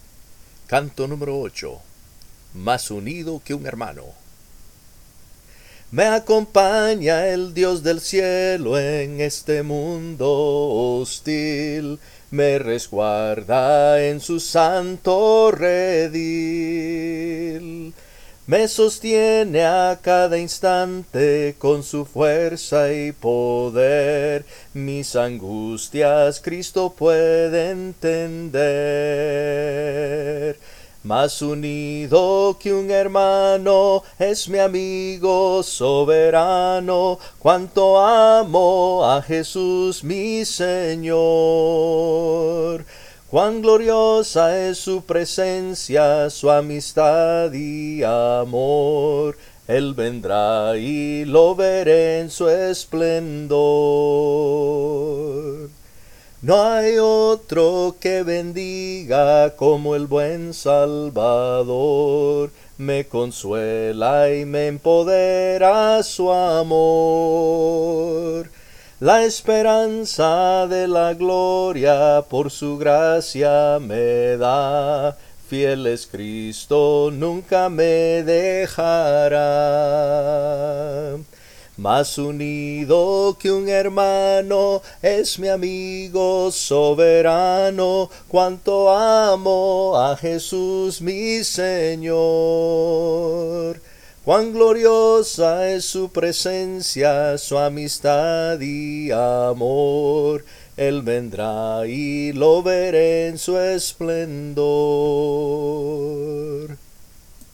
Se ha optado por interpretar únicamente la melodía, prescindiendo de las voces de armonía (alto, tenor y bajo) con el propósito de facilitar el proceso de aprendizaje. Al centrarse exclusivamente en el soprano (la melodía), el oyente puede captar con mayor claridad las notas y matices sonoros.